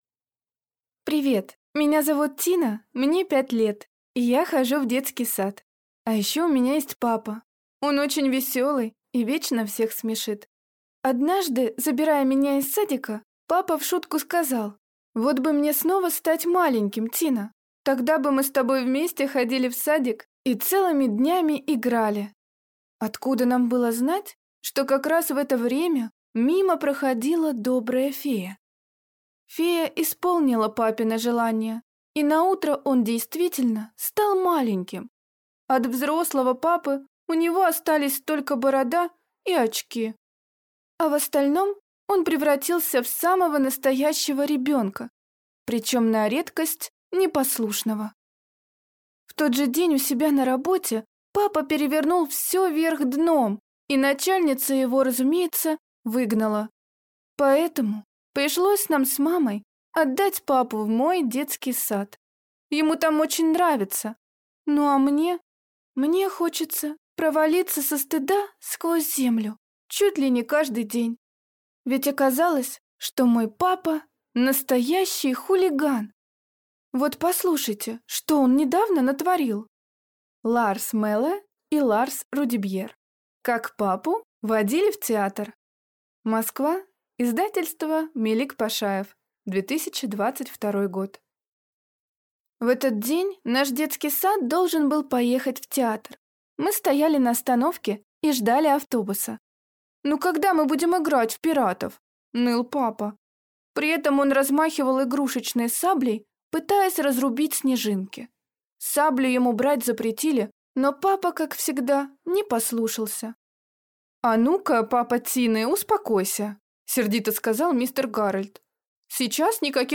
Аудиокнига Как папу водили в театр | Библиотека аудиокниг